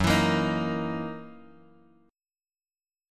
F#13 chord